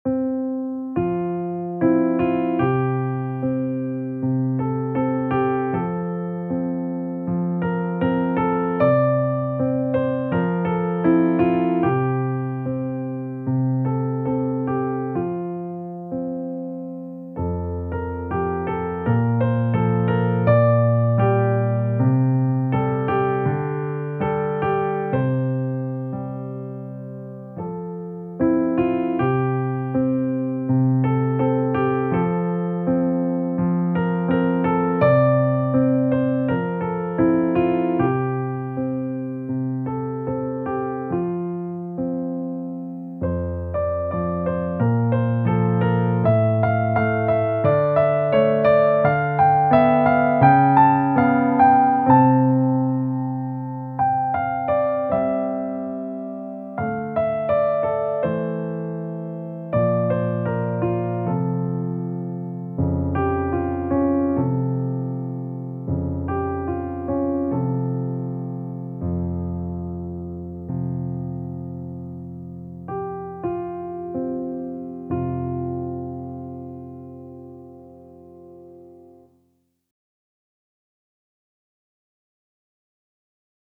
contexto histórico y grabaciones de referencia incluidas.
arreglos